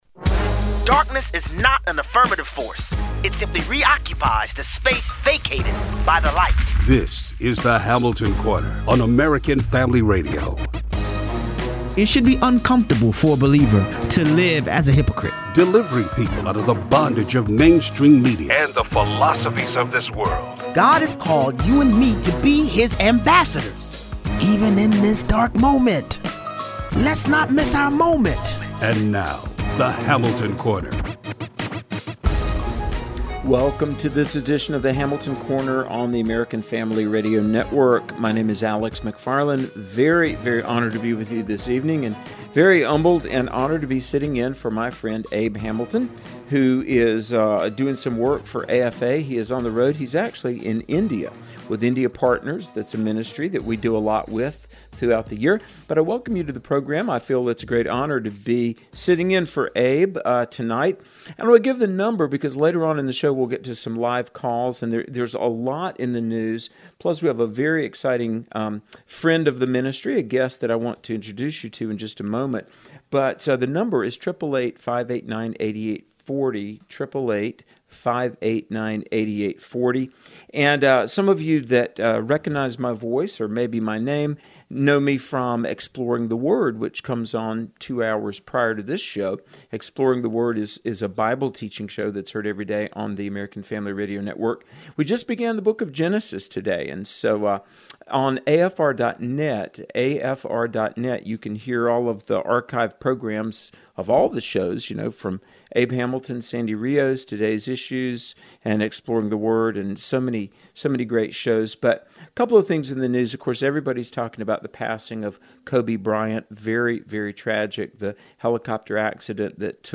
Listener Call Ins